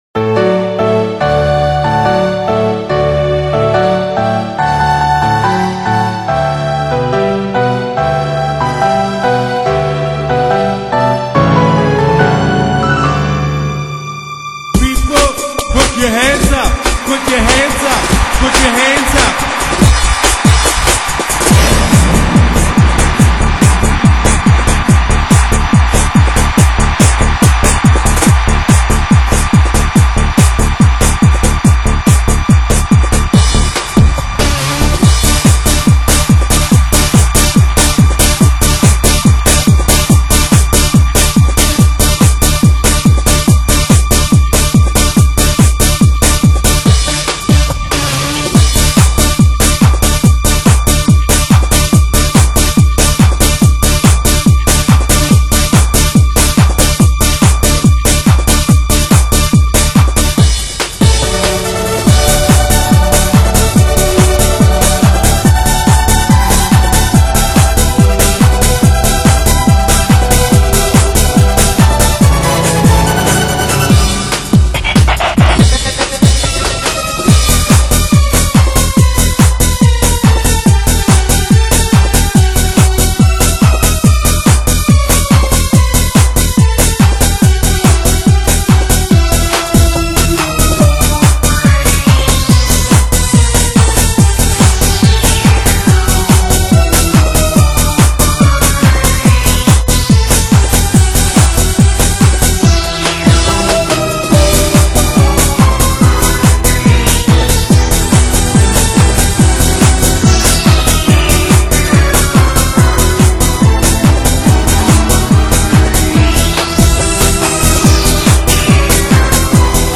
广告曲，融入电子加前段说唱音乐，快节奏的音乐给人另一种风格